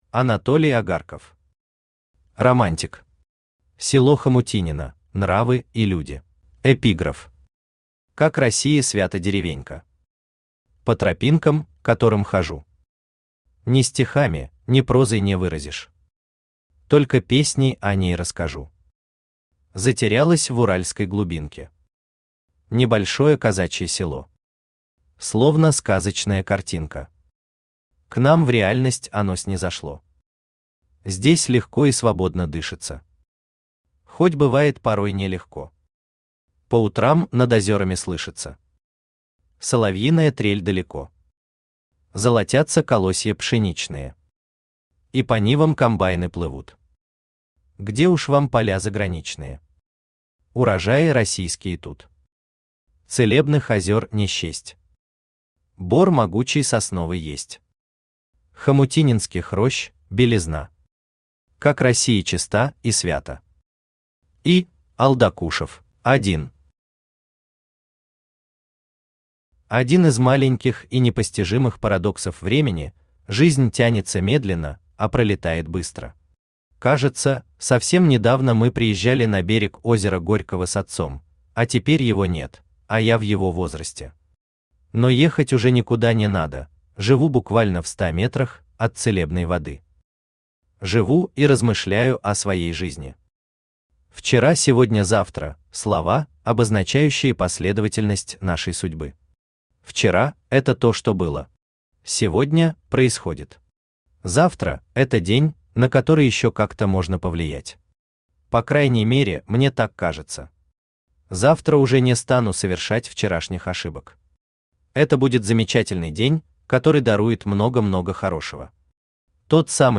Аудиокнига Романтик. Село Хомутинино: нравы и люди | Библиотека аудиокниг
Село Хомутинино: нравы и люди Автор Анатолий Агарков Читает аудиокнигу Авточтец ЛитРес.